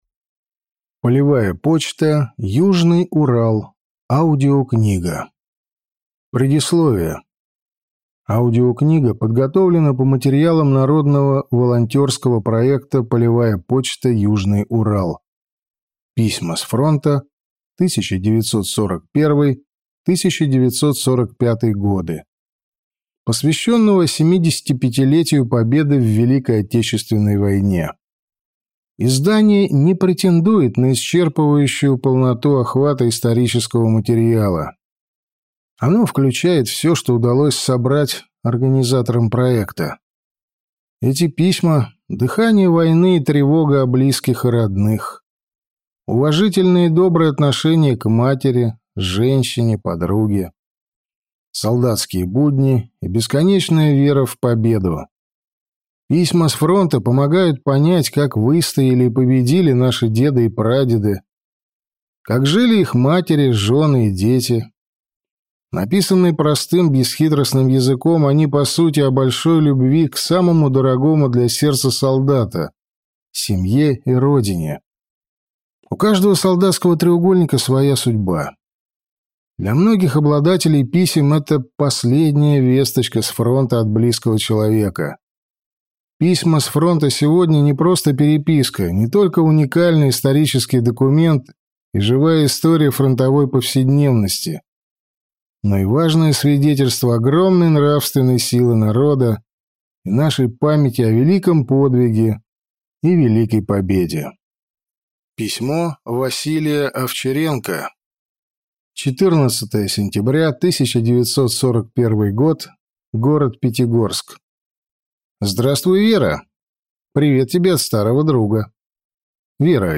Аудиокнига Полевая почта – Южный Урал. 1941 | Библиотека аудиокниг